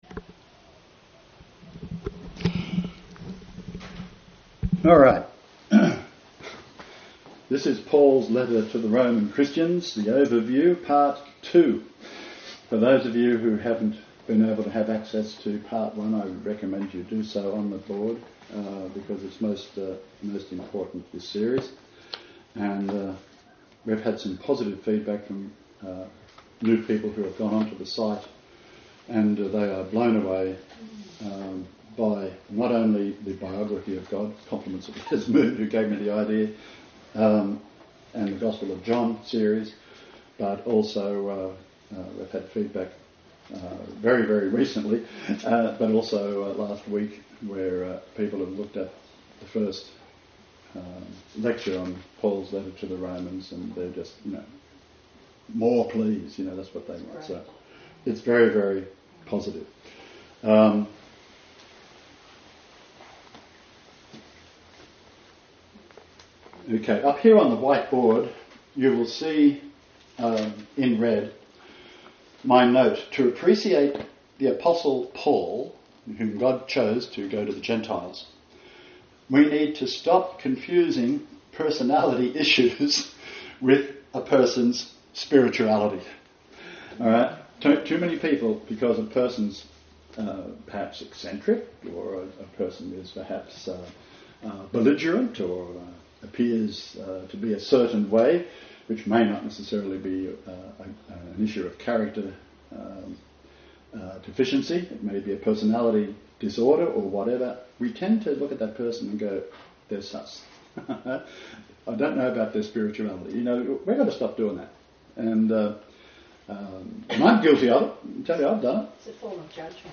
Lecture2.MP3